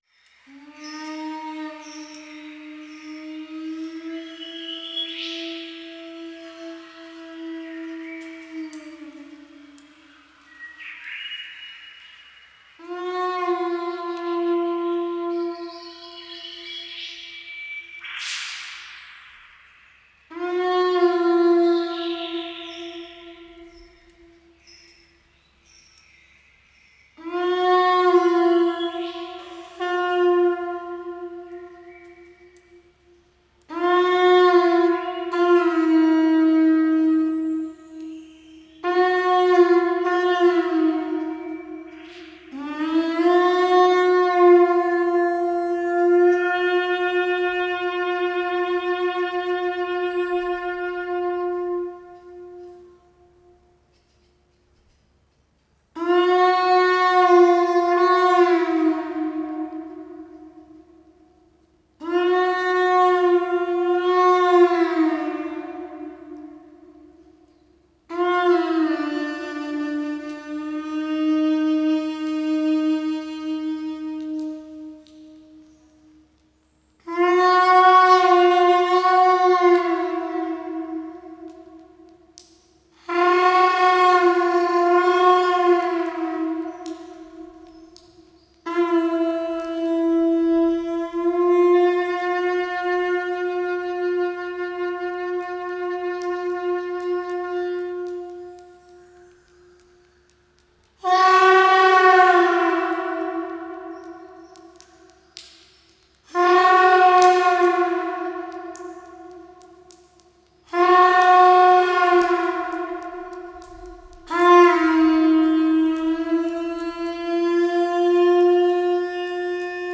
Genre: World Music
CD 5 Jenolan Caves / Earthharp